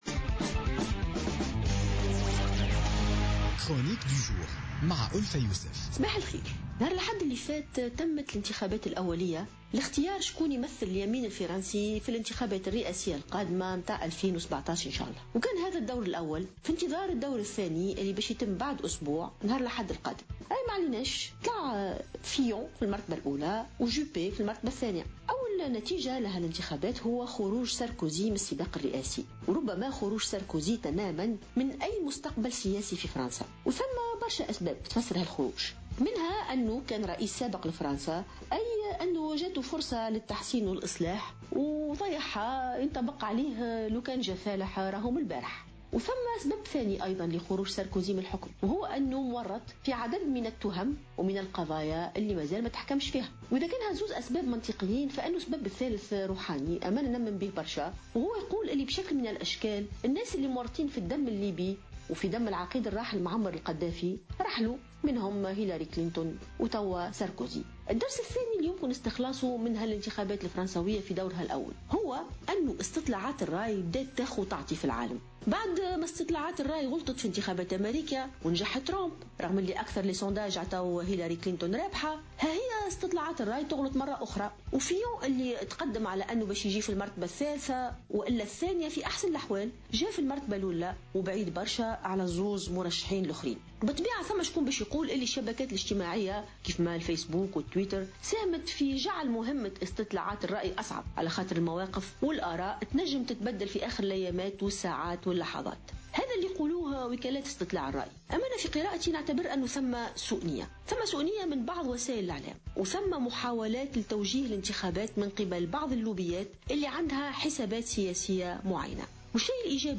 تحدثت الجامعية ألفة يوسف في افتتاحية اليوم الأربعاء عن تصدّر رئيس الوزراء الفرنسي السابق فرنسوا فيون الدورة الأولى من الانتخابات التمهيدية لاختيار مرشح اليمين الفرنسي و هزيمة نيكولا ساركوزي.